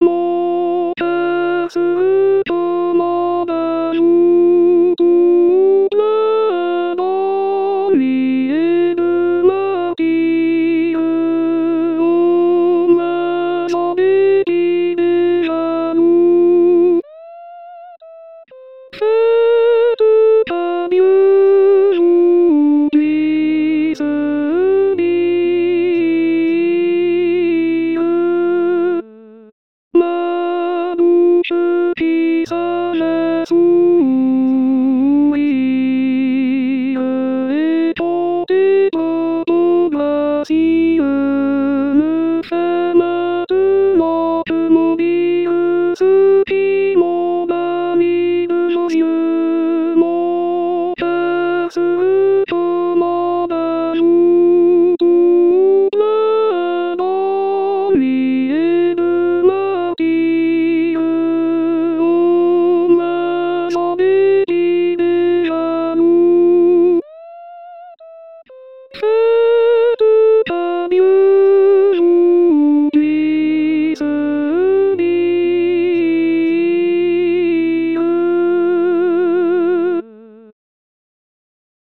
voix chantée IA